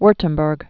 (wûrtəm-bûrg, vürtəm-bĕrk)